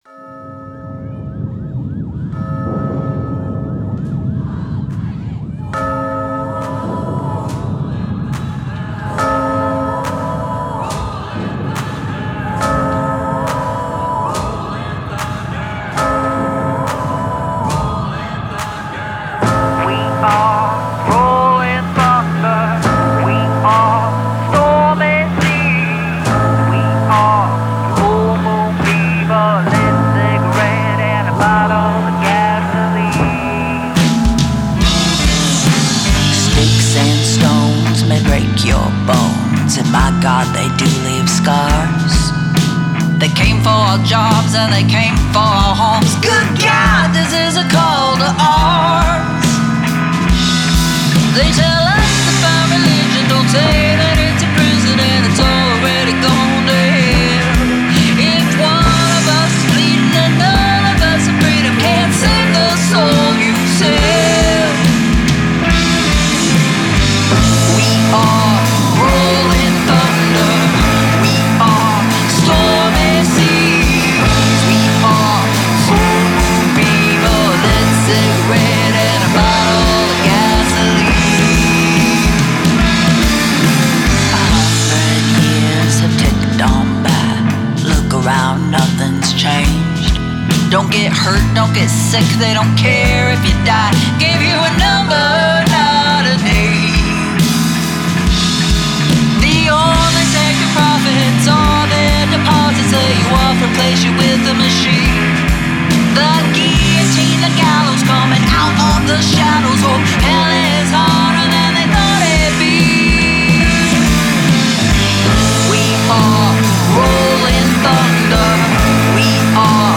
Drums/percussive soundscapes
Guitars & Organ
Key: Am | BPM: 70, 4/4 time